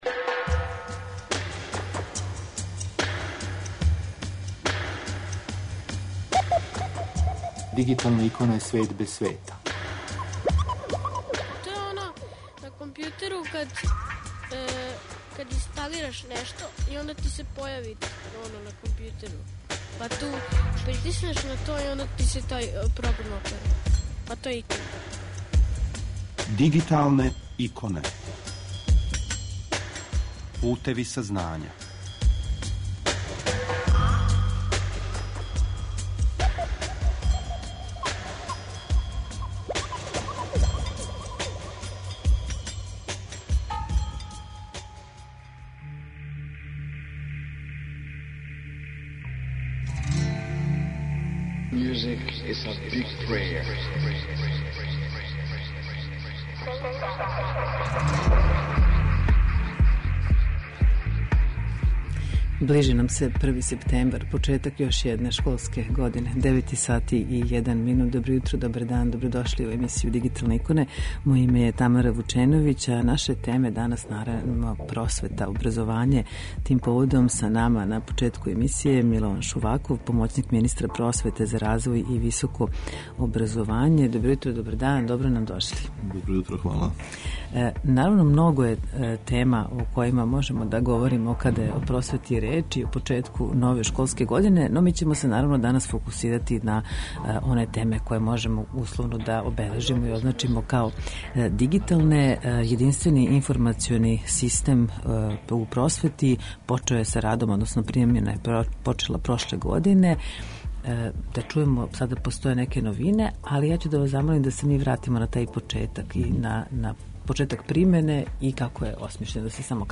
Милован Шуваков, помоћник министра просвете за развој и високо образовање, први је саговорник у данашњим Дигиталним иконама. Тема разговора је примена и новине у обједињеном информационом систему у просвети.